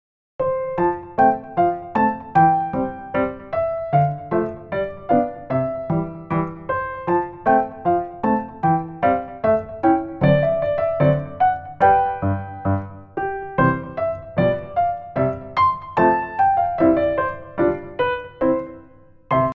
モチーフの続きはFのコードで明るくします。
盛り上がった雰囲気にしなければならないので、明るい和音の Fから始めます。
BのあとはAの戻って終わりです。